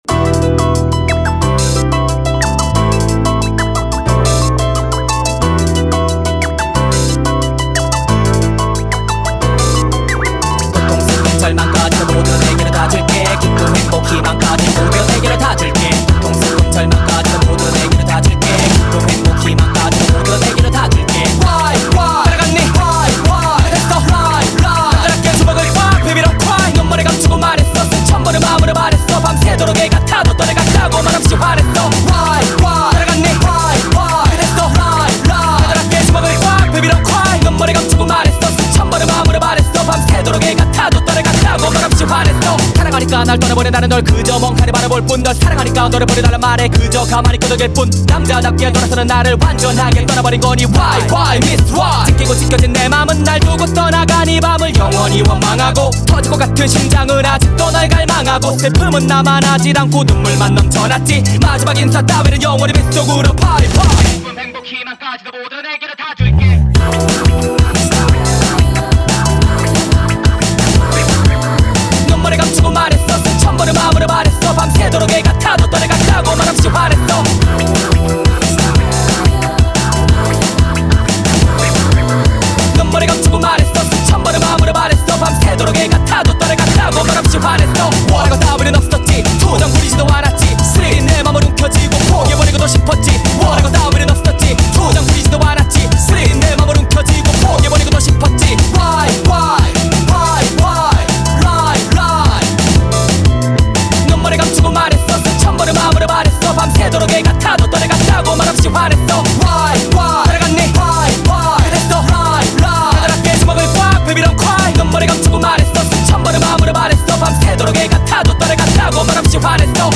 BPM180
MP3 QualityMusic Cut